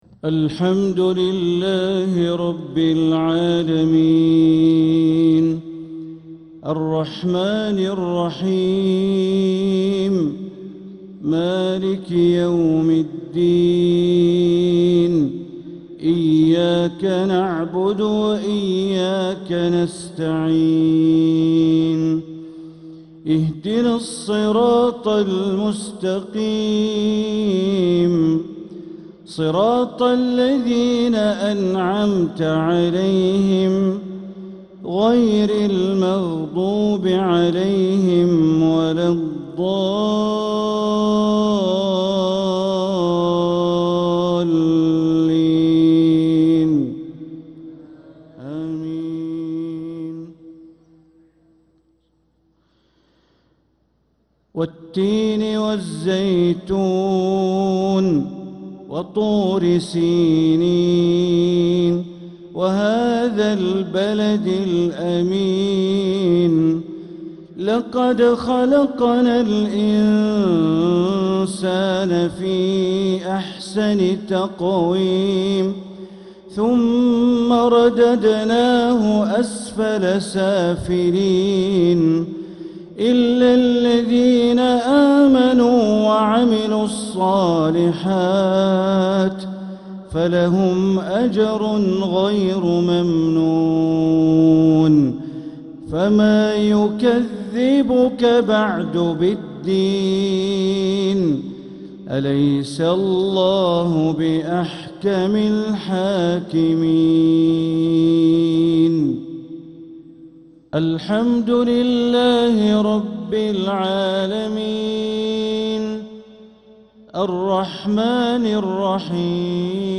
مغرب الأربعاء 6-8-1446هـ سورتي التين و الزلزلة كاملة | Maghrib prayer Surat at-Tin & Az-Zalzala 5-2-2025 > 1446 🕋 > الفروض - تلاوات الحرمين